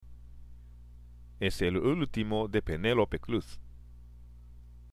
（エス　エル　ウルティモ　デ　ペネロペ　クルス）